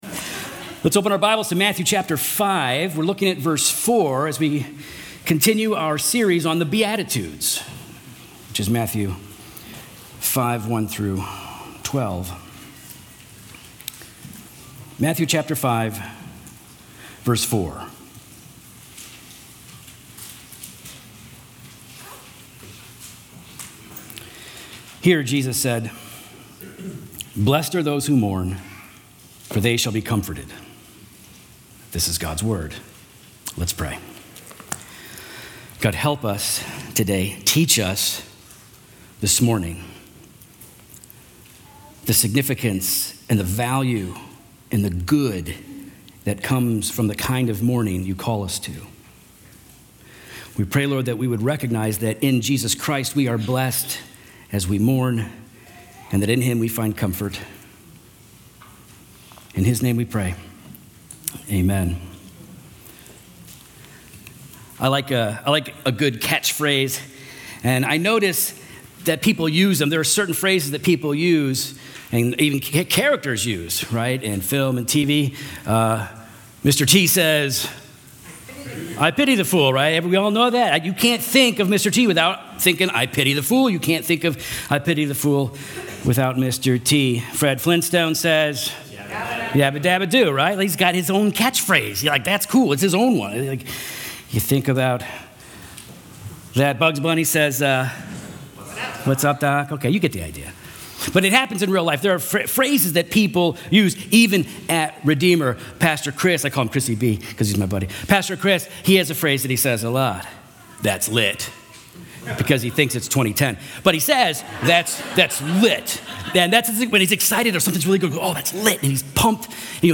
Redeemer Fellowship Sermons Podcast - The Mourning | Free Listening on Podbean App